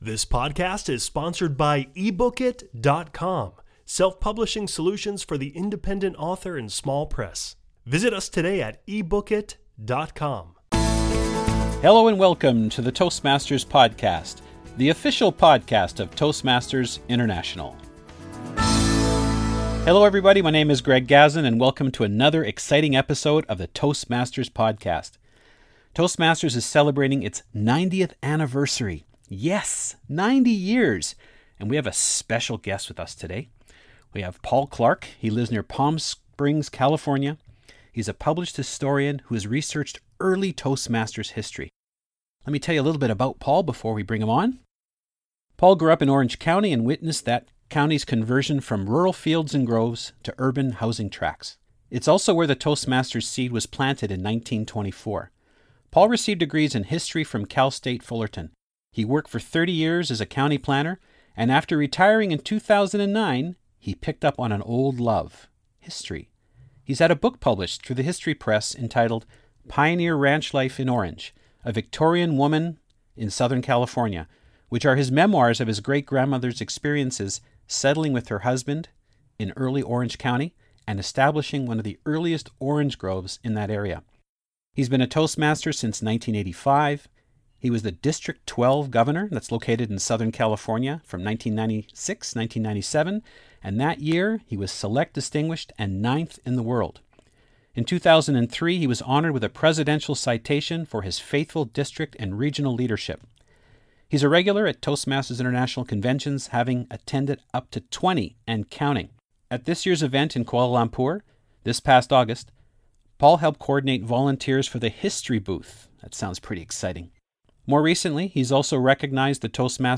a historian and author who has researched early Toastmasters history